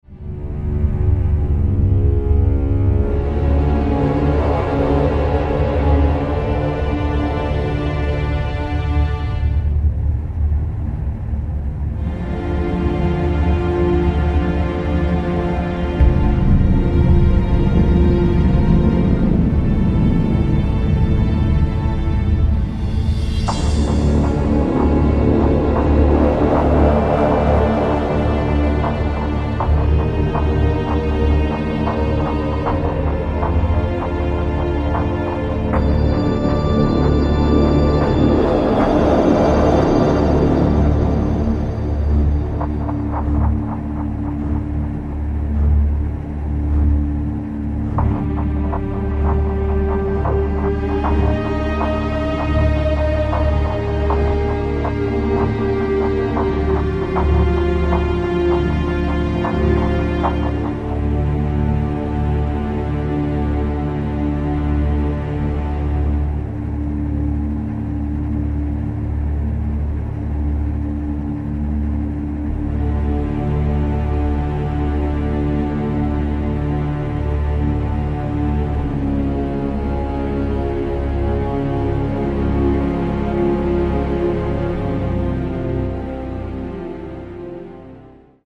2 ambient tracks and 11 orchestral styled tracks.